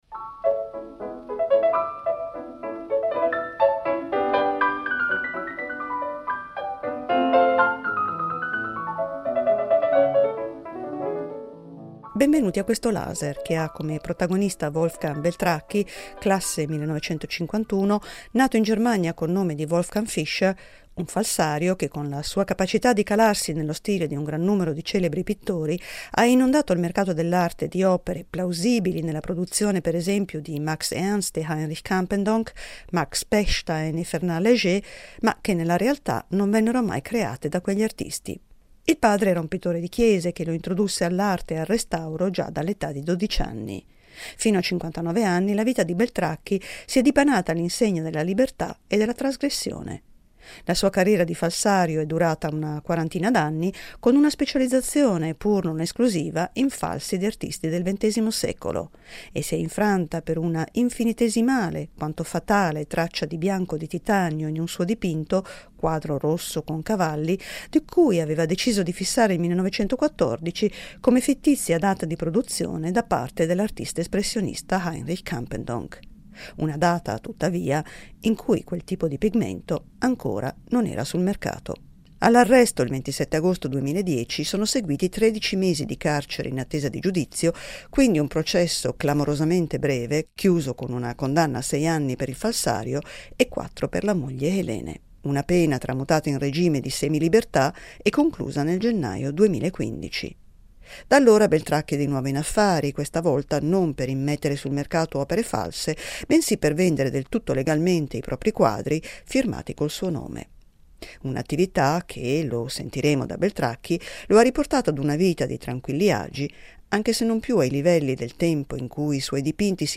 Voce italiana di Wolfgang Beltracchi